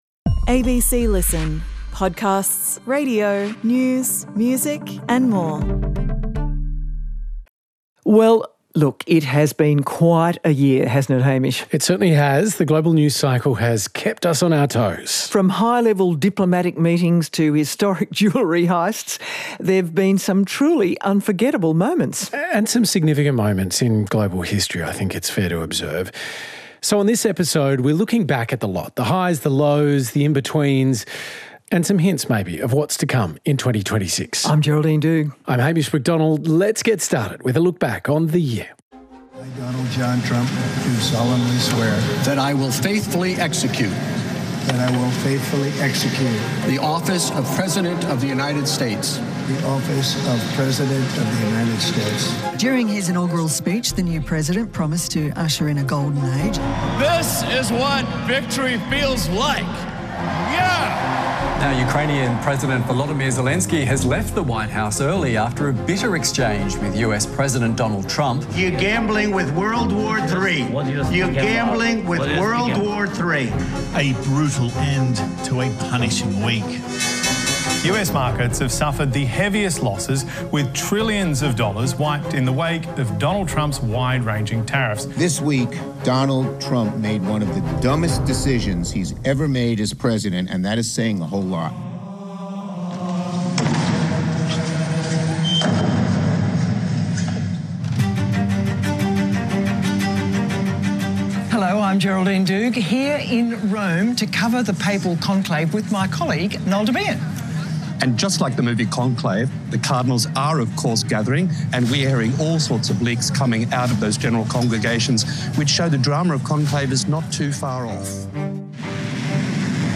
Two award-winning journalists with decades of experience reporting on major world events, Geraldine Doogue and Hamish Macdonald join forces for a fresh conversation about global news and how to make sense of it. Along with expert guests, they take a single topic and examine it with Australian eyes.